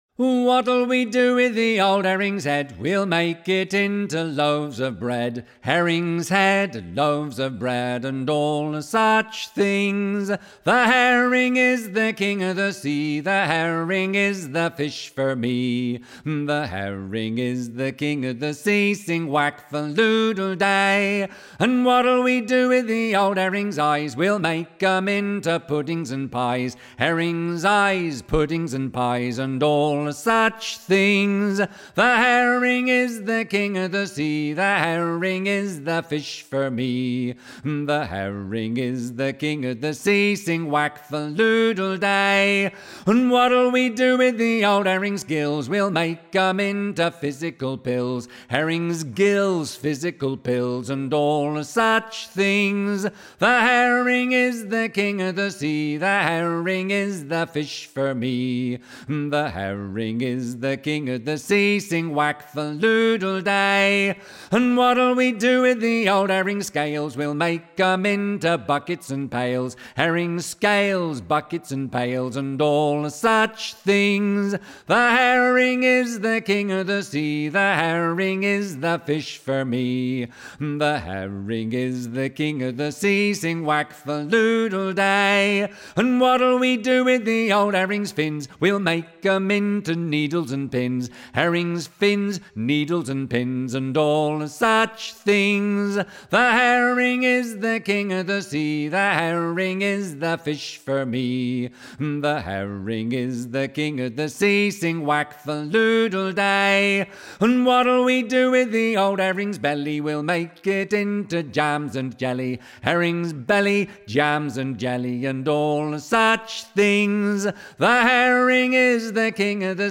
þjóðlagasöngvara